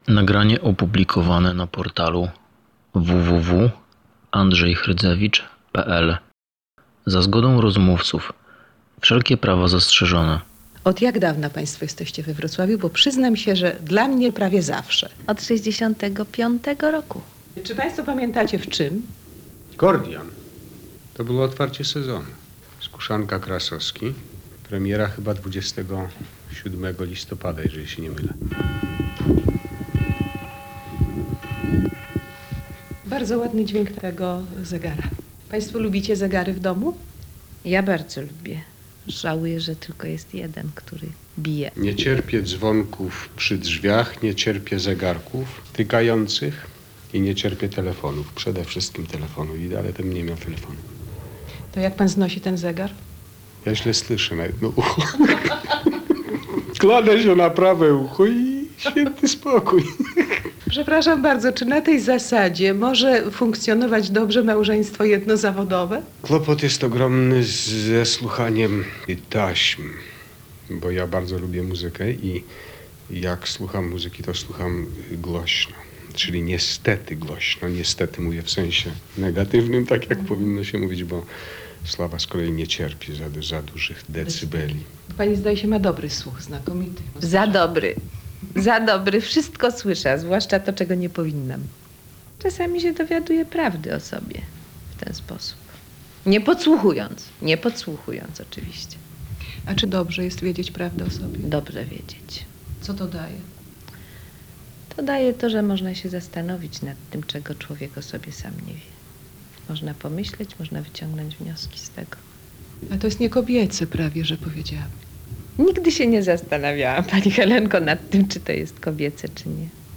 Wywiad dla Radia Wrocław